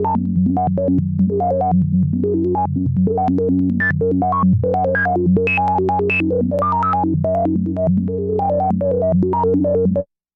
标签： midivelocity96 F4 midinote66 OberheimXpander synthesizer singlenote multisample
声道立体声